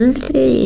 描述：bab呀学语的女人
Tag: 咿呀学语 沃玛 咿呀学语